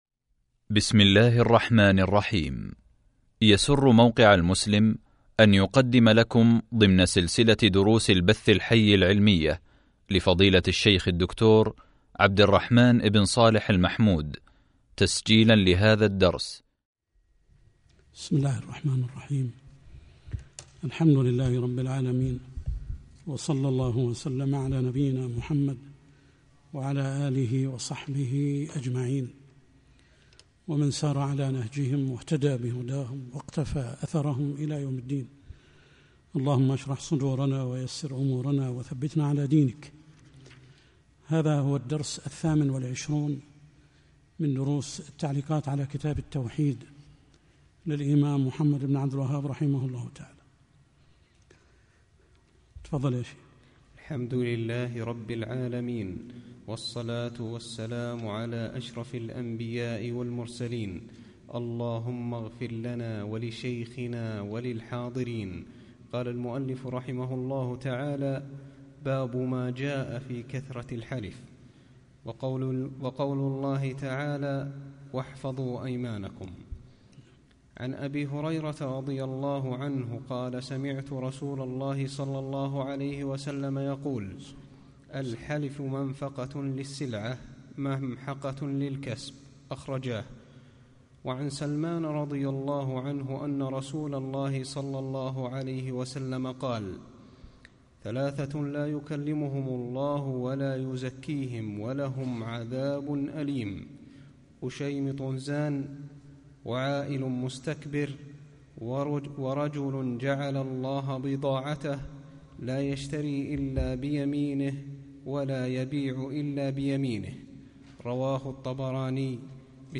شرح كتاب التوحيد | الدرس 28 | موقع المسلم